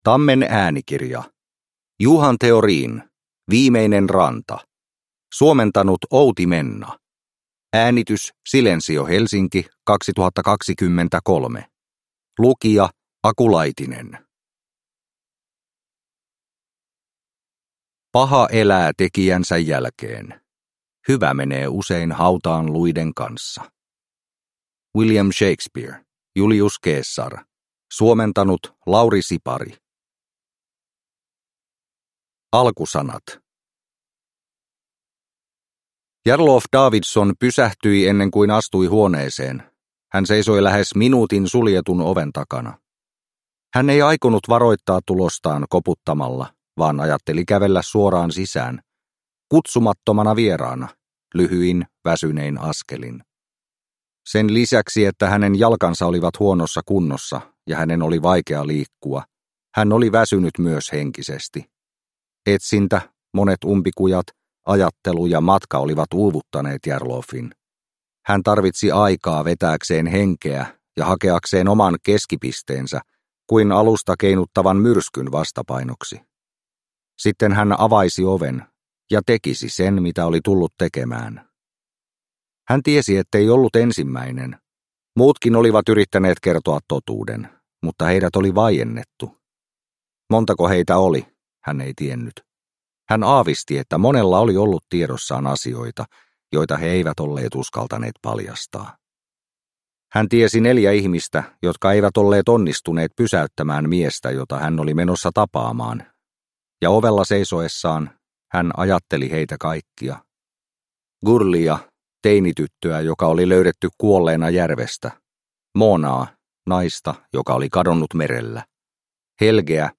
Viimeinen ranta – Ljudbok – Laddas ner